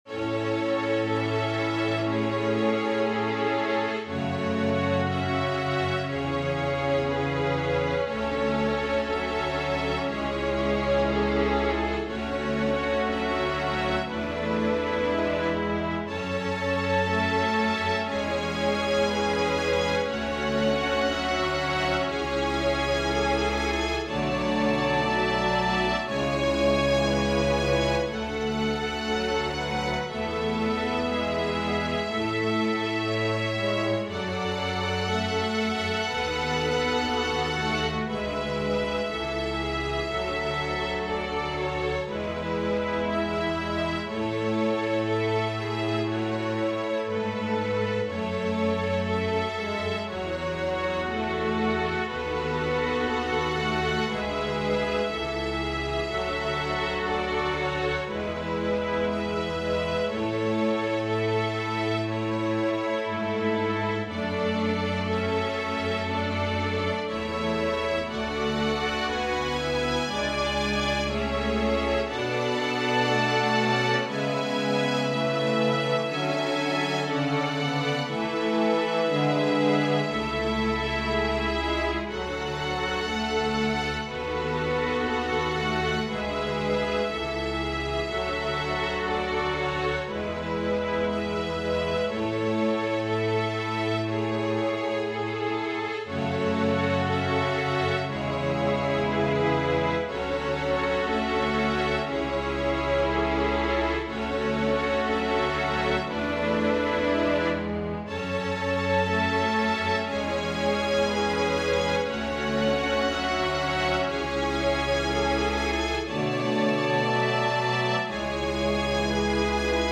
Welsh lullaby
adapted for organ solo